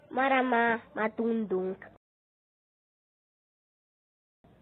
marama madungdung Meme Sound Effect